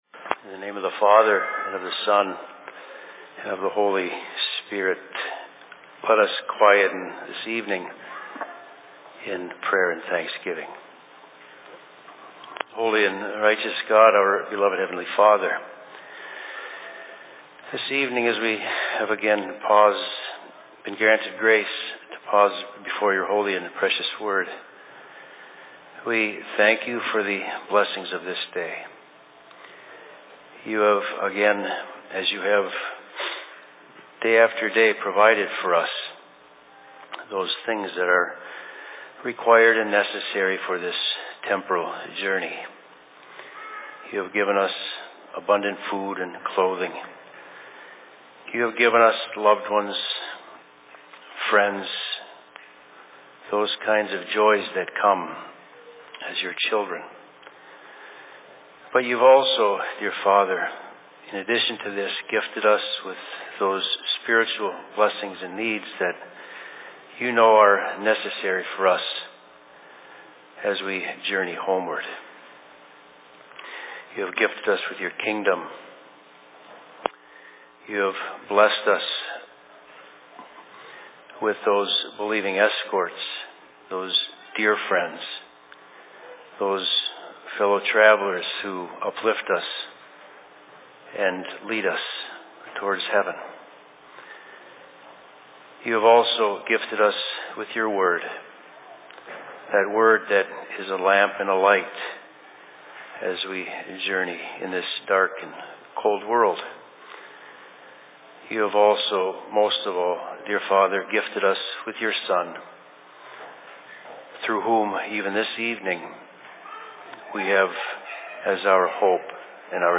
Sermon in Rockford 10.01.2016
Location: LLC Rockford